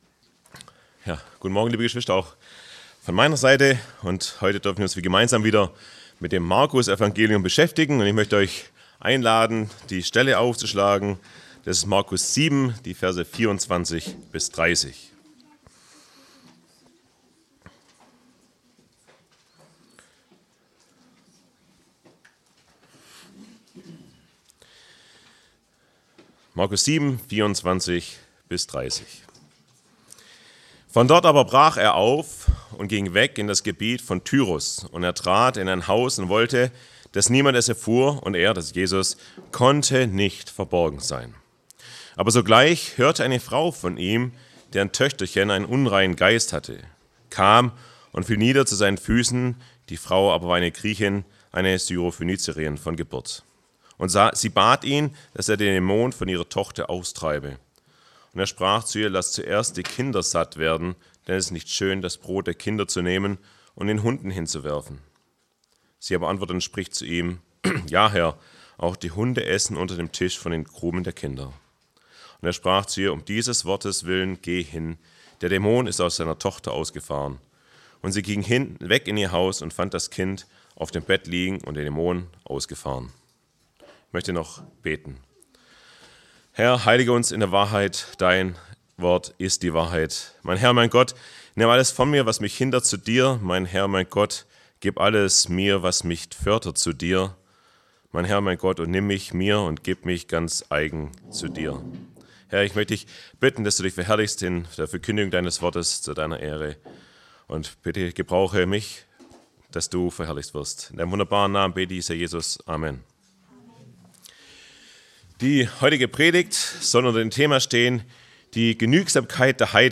Predigtreihe: Markusevangelium Auslegungsreihe